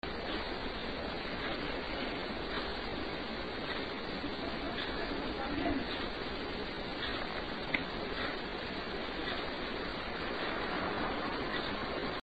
It is much deeper toned than previous messages from him.
Here are the cleaned up EVPs. They are far from “Class As” but there is someone there!